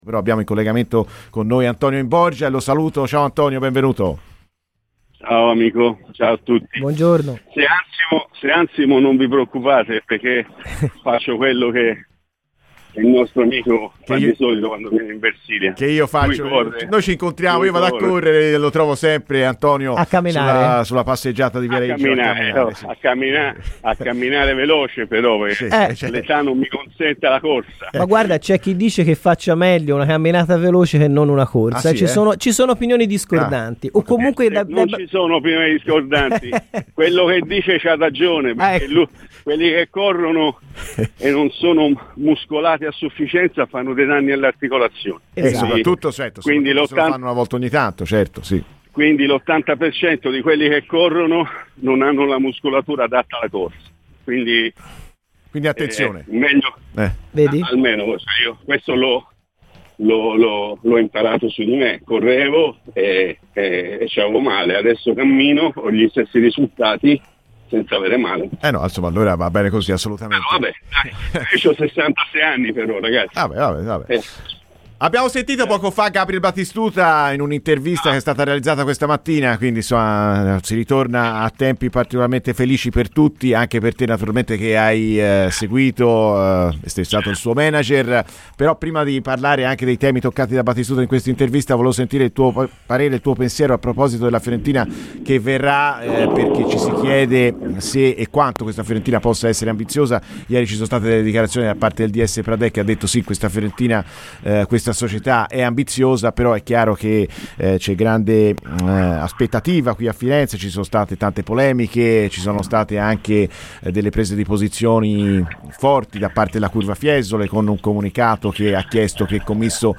PER L'INTERVISTA COMPLETA ASCOLTA IL NOSTRO PODCAST!